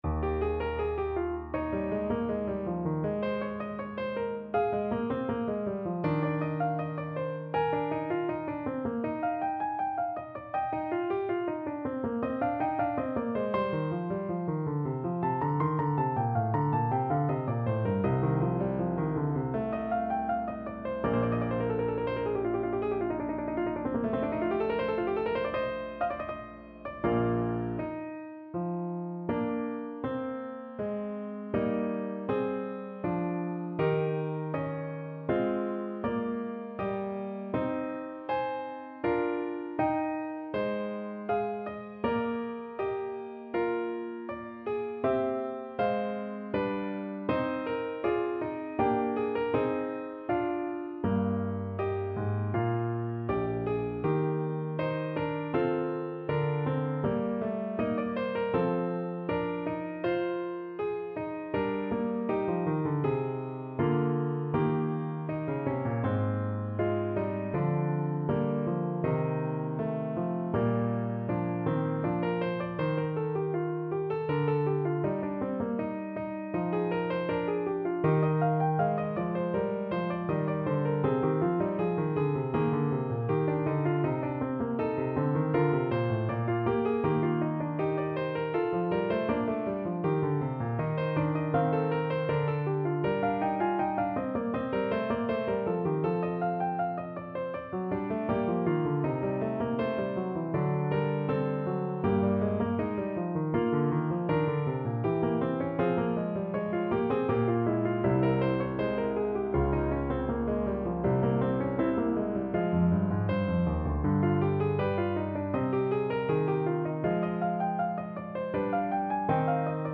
Piano version
Piano  (View more Advanced Piano Music)
Classical (View more Classical Piano Music)